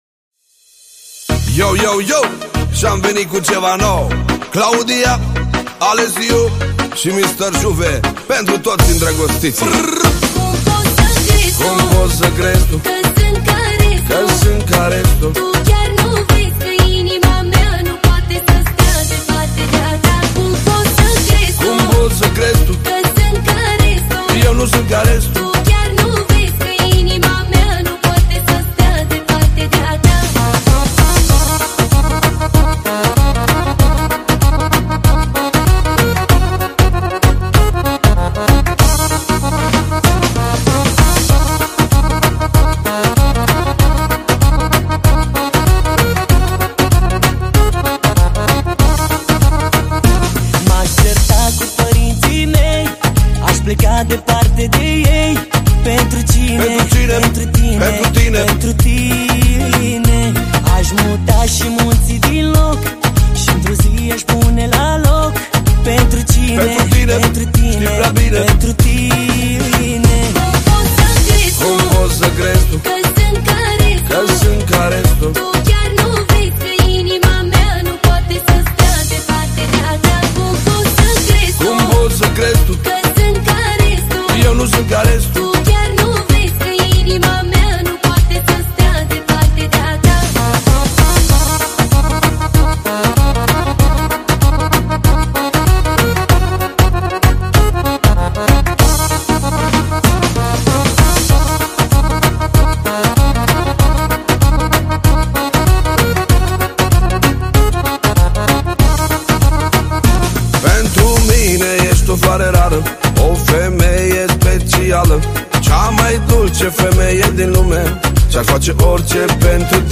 o piesă care îmbină emoție și ritm
Manele Vechi